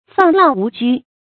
放浪無拘 注音： ㄈㄤˋ ㄌㄤˋ ㄨˊ ㄐㄨ 讀音讀法： 意思解釋： 見「放浪不羈」。